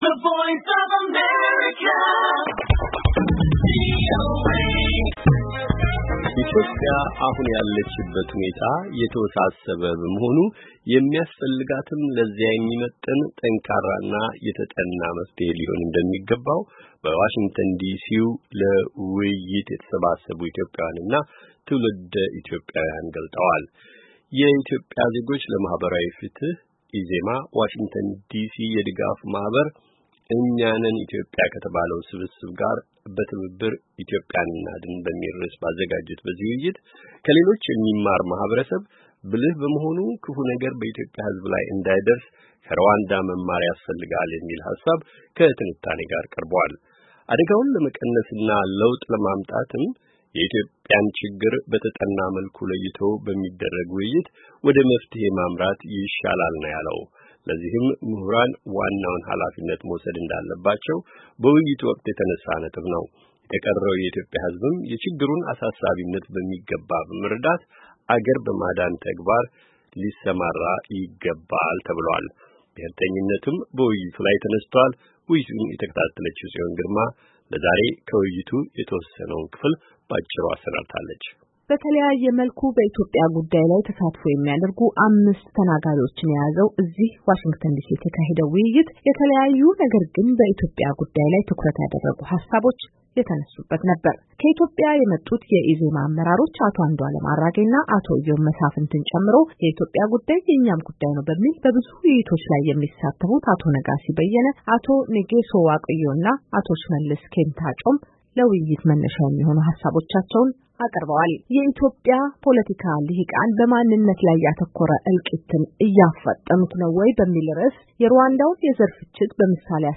ኢትዮጵያ አሁን ያለችበት ሁኔታ የተወሳሰበ በመሆኑ የሚያስፈልጋትም የተወሳሰበው የሚፈታ፣ ጠንካራና የተጠና መፍትሔ ሊሆን እንደሚገባው በዋሽንግተን ዲሲ ለውይይት የተሰባሰቡ ኢትዮጵያውያን እና ትውልደ ኢትዮጵያ ገለፁ።